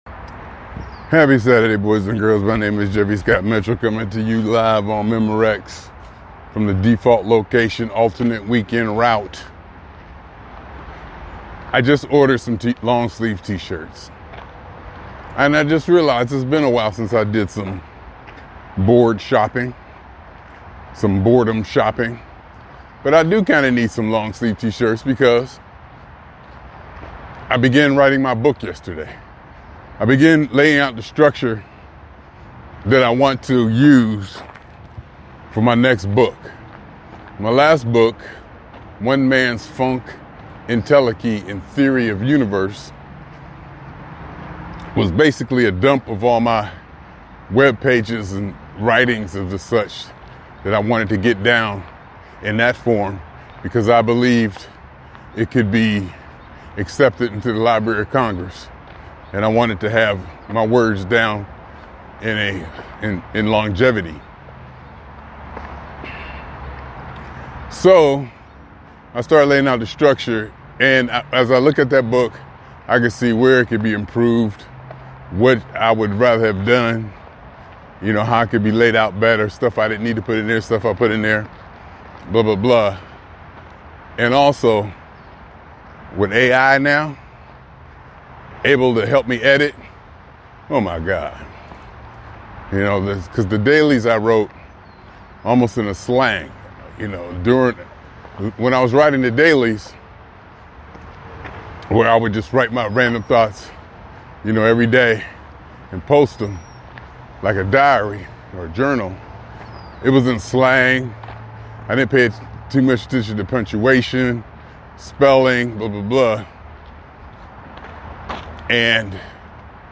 In this talk